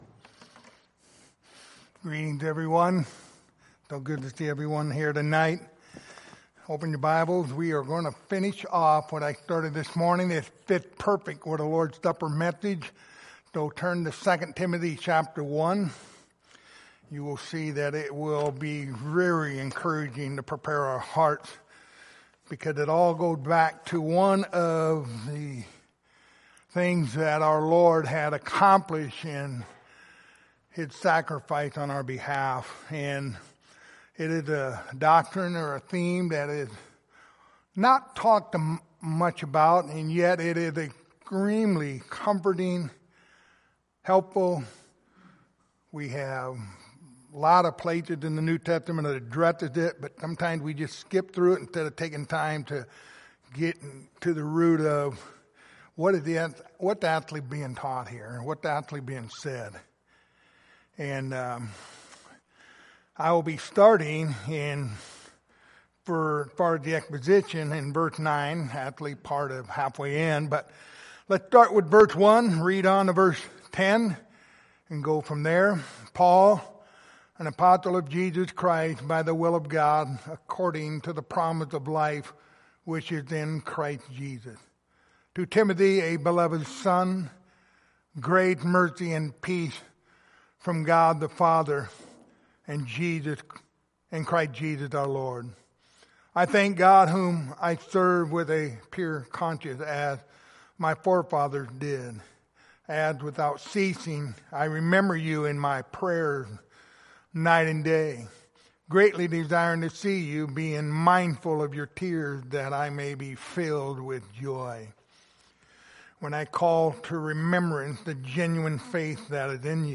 Lord's Supper Passage: 2 Timothy 1:8-10 Service Type: Lord's Supper Topics